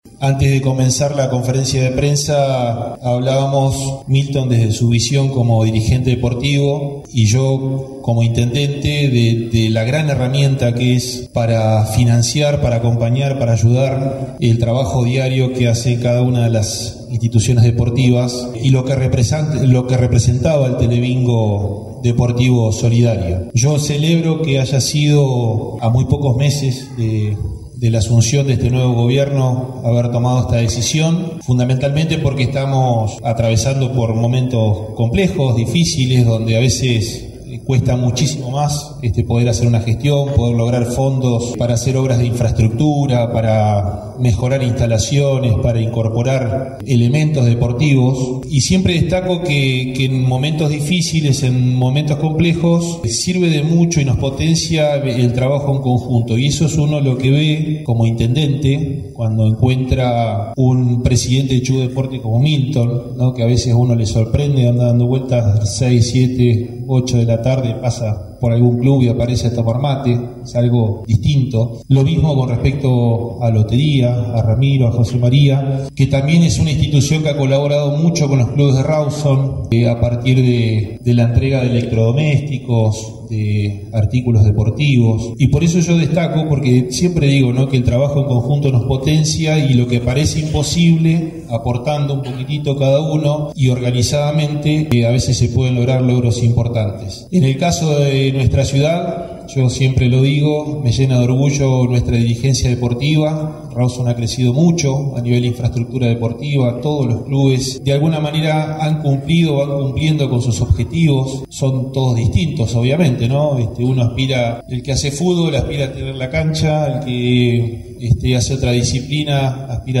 Con la participación del intendente Damián Biss por la Municipalidad de Rawson junto al presidente de Chubut Deportes, Milthon Reyes y la participación del  gerente general de Lotería, Ramiro Ibarra, se llevo a cabo en la capital provincial, la noche del lunes 29 de abril, la presentación del Telebingo Deportivo que se sorteara en aproximadamente sesenta días aproximadamente.
El intendente de la capital chubutense dio la bienvenida y destaco la propuesta.